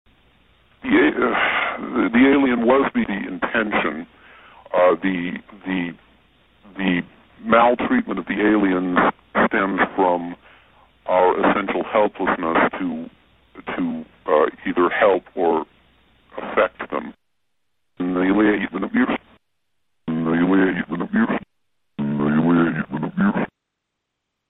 最大の難点は、 ヴォイスチェンジャーによって音声が聞きづらくなっていたことだった。
(mp3) というリバース・スピーチが現れていた。